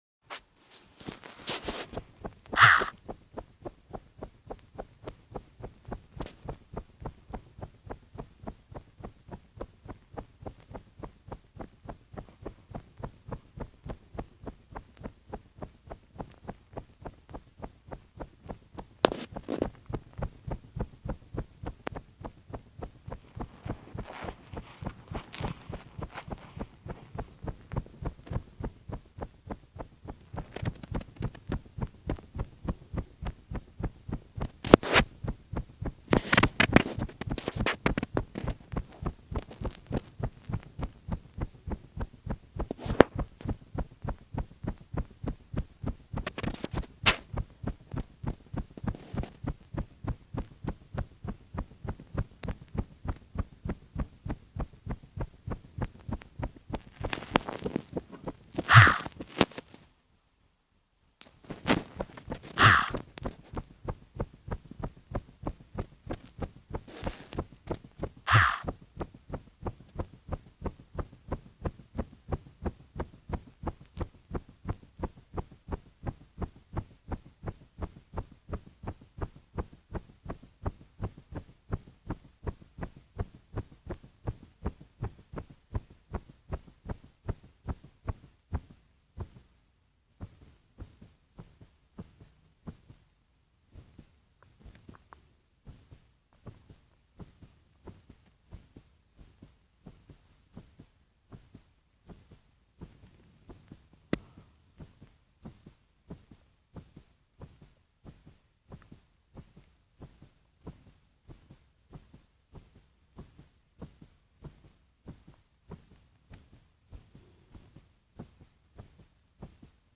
І я вам це продемонструю, бо записала стук свого серця.
Там де я хекаю, то це я так видихала, бо думала зупинити ці вібрації.
Де чути тріск — то це зашкал на лінії звуку телефону, бо я спостерігала за ним, коли записувала.
Звук биття серця такий гучний, що здається ніби кімната наповнилась звуком поїзда, що їде на великій швидкості: чих-чах.
Принесла його з іншої кімнати, сіла в крісло і приставила до грудей. Через трошки ритм почав спадати і все ввійшло в звичний ритм, про що можна догадатись зі звуку.